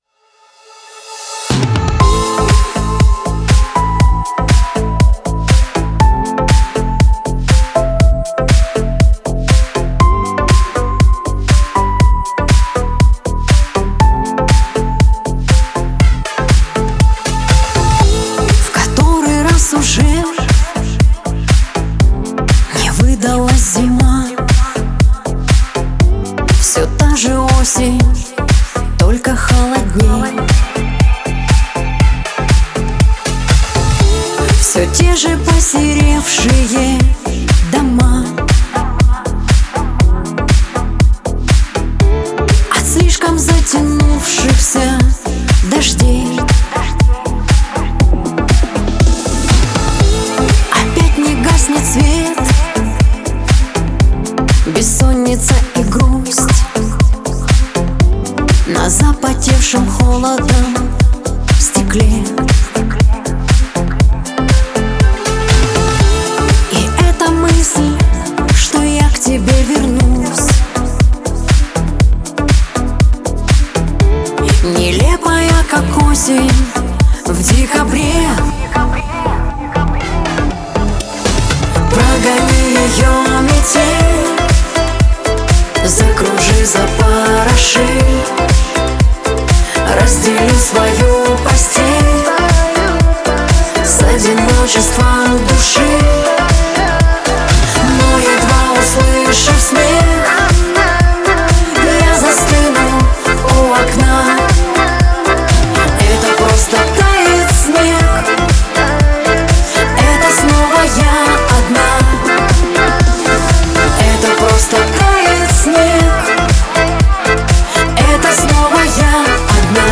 Трек размещён в разделе Русские песни / Шансон / 2022.